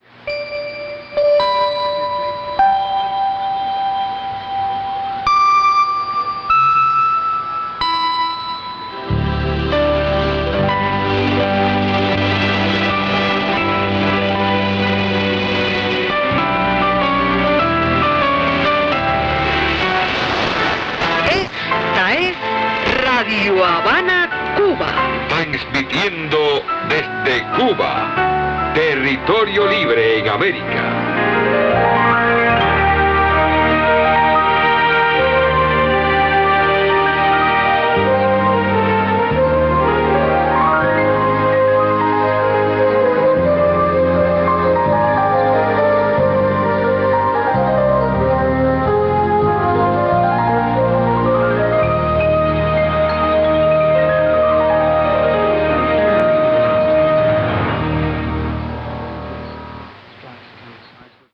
Identificación Radio Habana Cuba
La señal de intervalo, que se emite antes de comenzar y al terminar sus transmisiones consiste de los primeros compases de la Marcha del Movimiento Revolucionario "26 de Julio".
Fecha de grabación: 22 de diciembre de 2010 Equipo: Radio de onda corta marca Yaesu FT-757, banda de 19 metros.